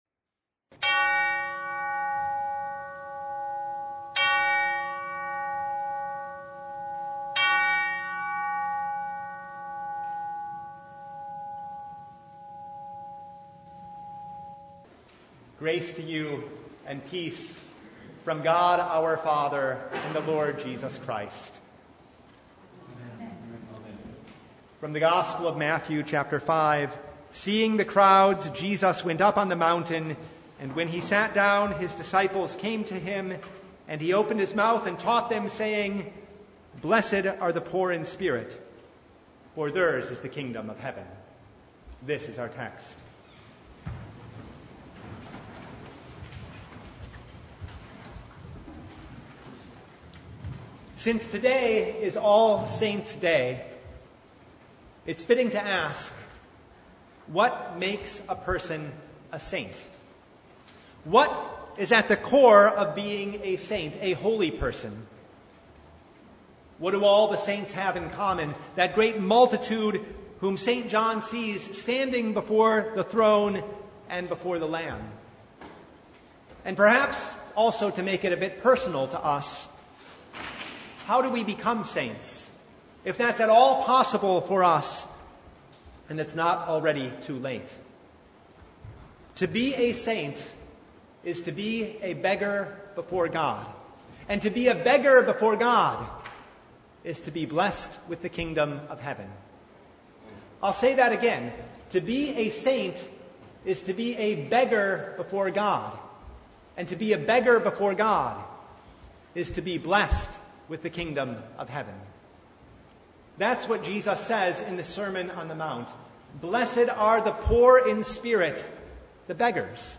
Matthew 5:1-12 Service Type: The Feast of All Saints' Day The Holy Spirit